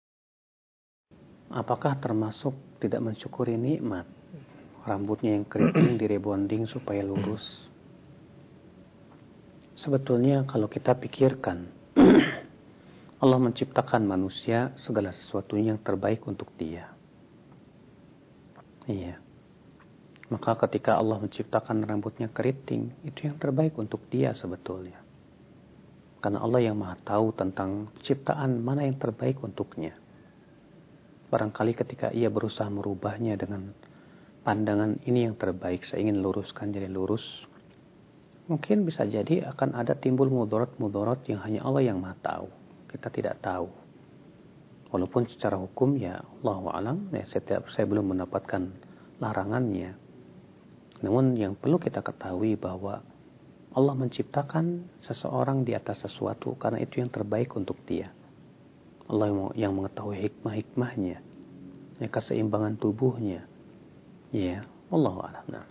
Simak penjelasan